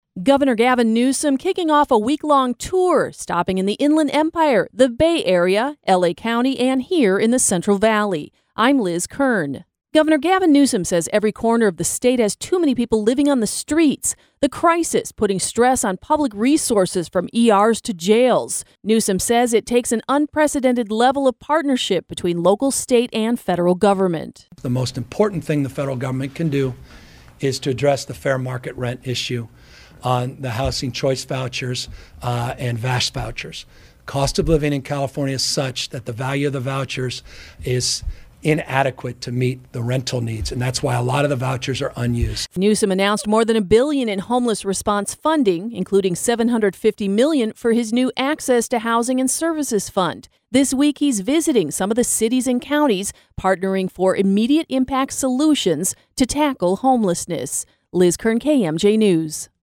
LK-WEB-NEWSOM-HOMELESS-TOUR.mp3